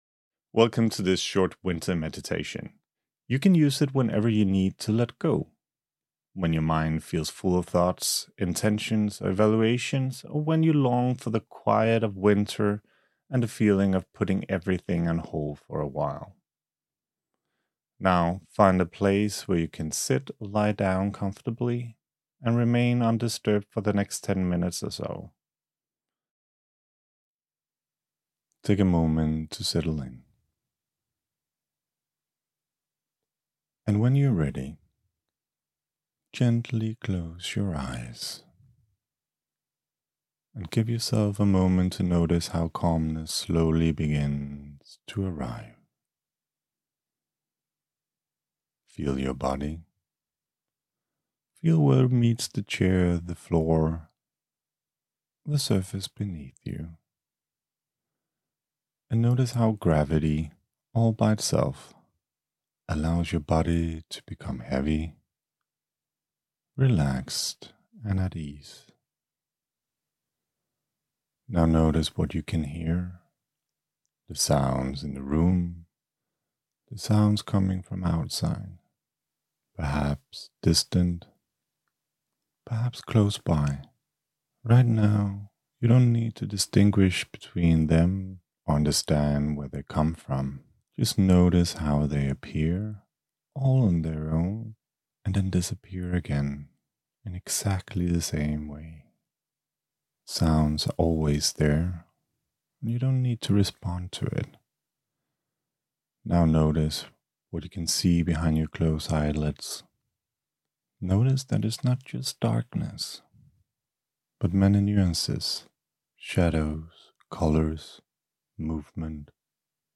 A guided visualization to turn inward, find calm, and make space for new beginnings – inspired by the wisdom of winter.